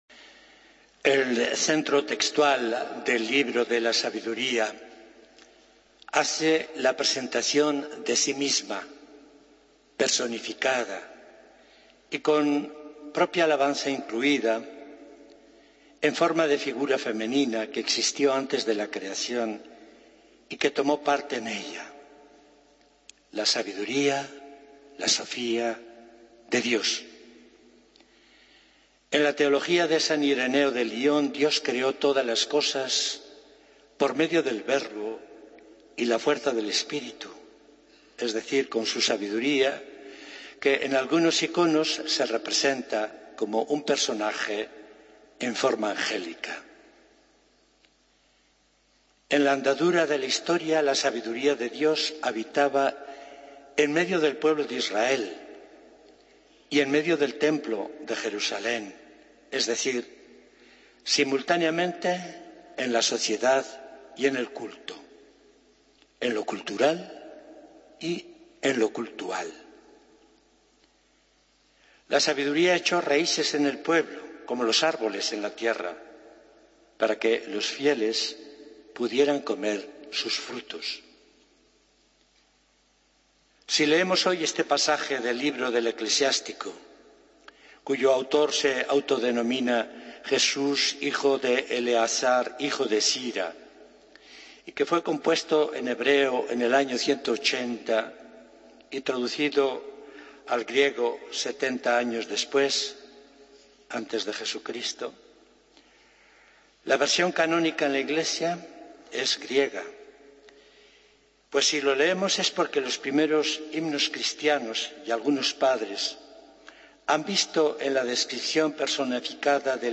Homilía del 04 de Enero de 2015